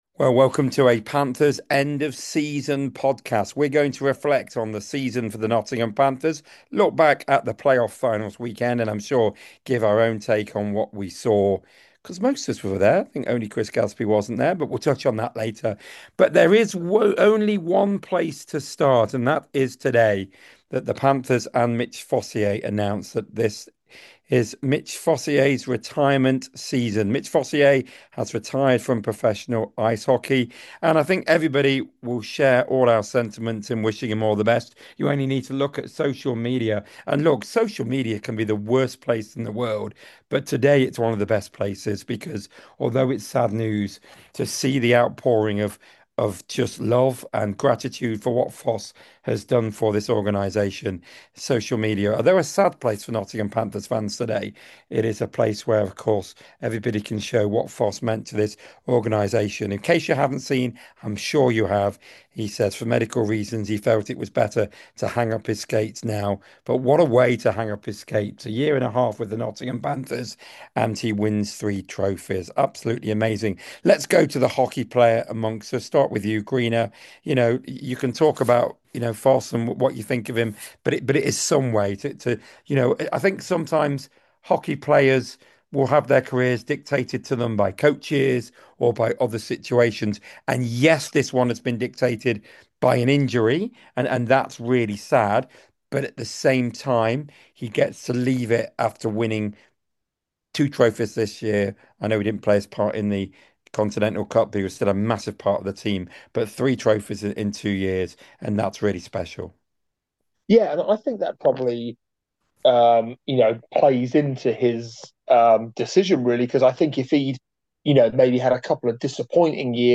The Nottingham Panthers podcast crew give their tributes after Mitch Fossier announced his retirement from professional ice hockey on Thursday.